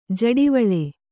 ♪ jaḍivaḷe